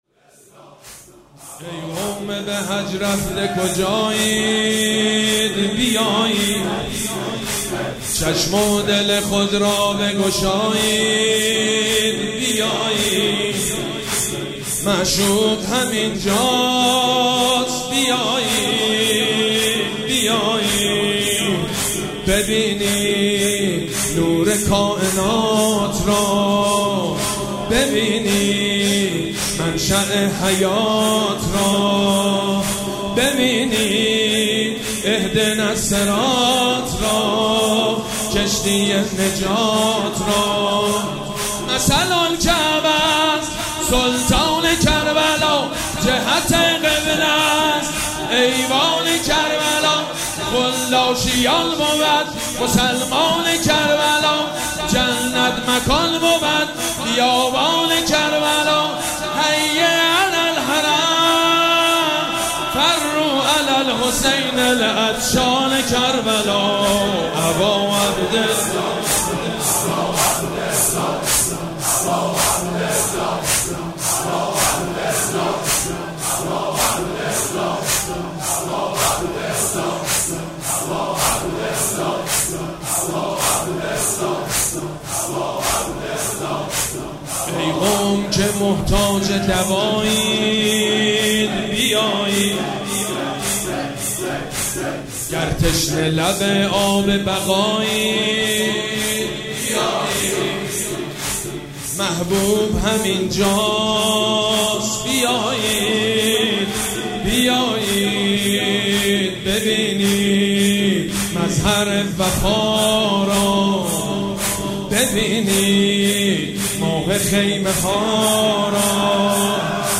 مراسم عزاداری شب دوم محرم الحرام ۱۴۴۷
مداح